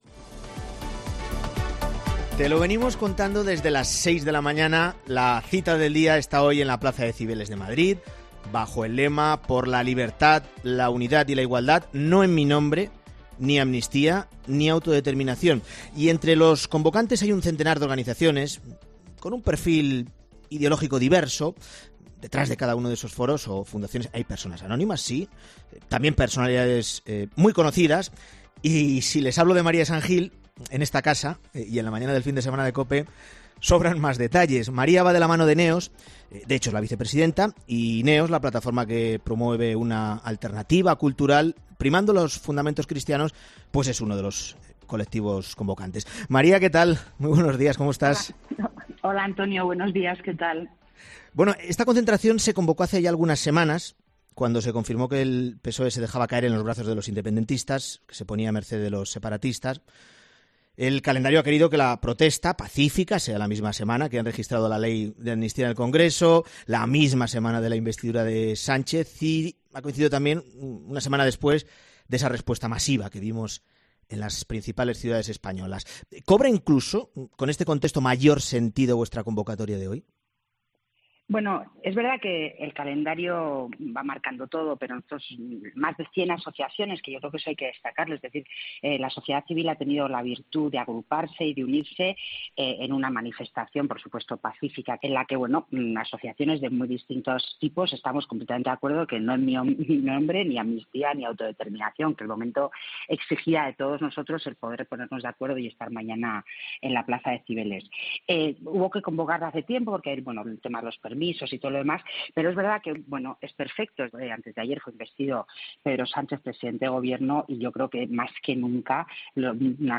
La viecepresidenta de NEOS ha estado en 'La Mañana Fin de Semana' horas antes de que comience una manifestación apoyada por más de cien asociaciones